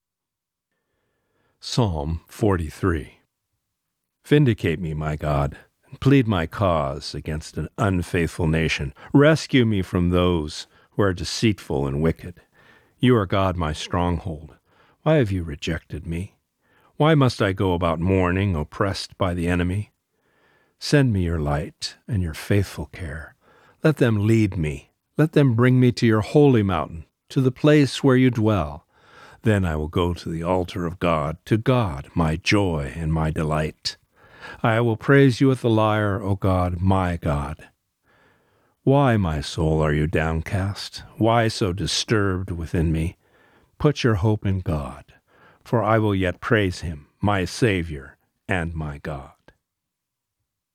Reading: Psalm 43